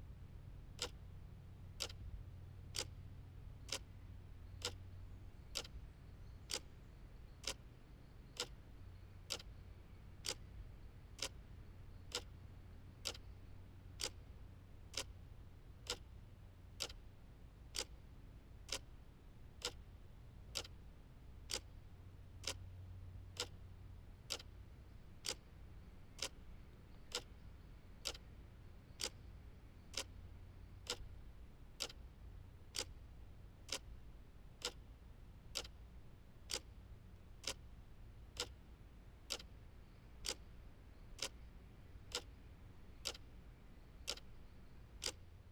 环境音